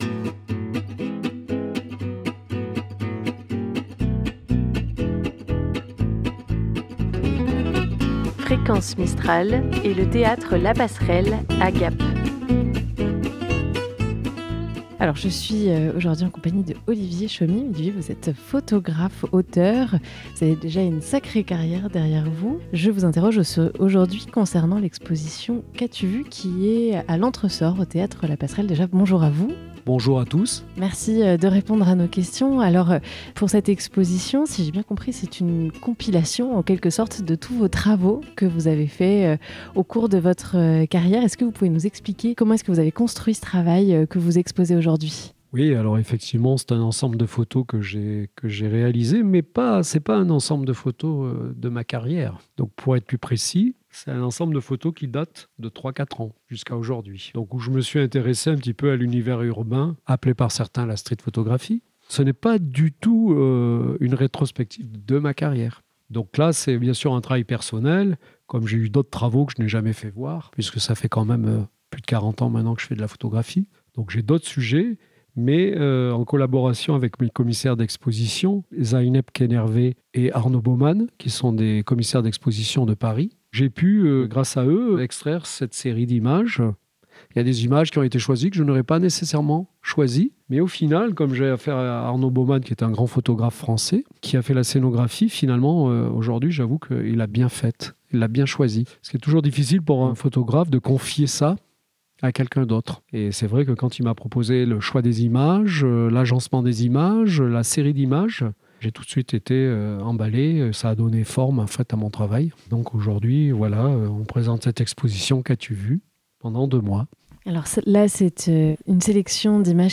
En amont du vernissage, le 13 janvier 2026 , nous avons pu échanger avec le photographe. L'occasion de revenir avec lui sur son attachement à un Noir & Blanc contrasté, sur sa manière de travailler "sur le vif", et encore beaucoup d'autres sujets. 250113